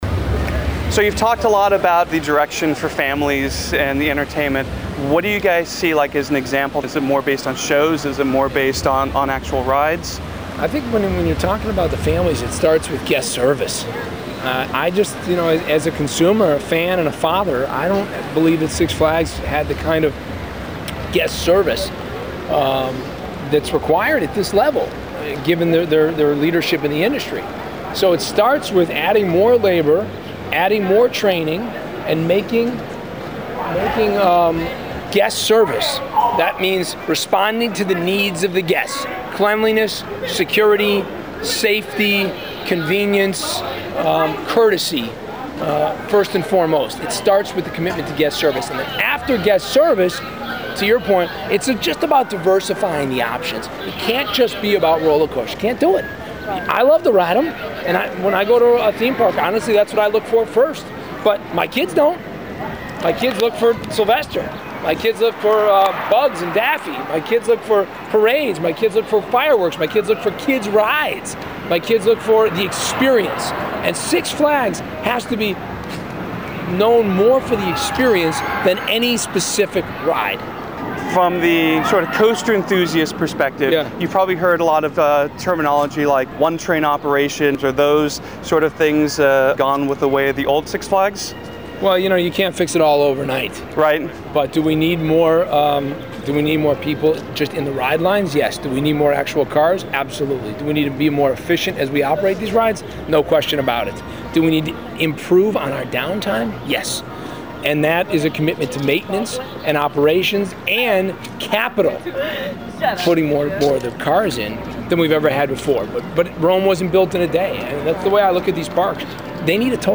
Click the above link to listen to Theme Park Review interviewing Six Flags CEO Mark Shapiro.
(This is an audio interview only)  If the streaming audio does not work